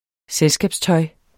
Udtale [ ˈsεlsgabs- ]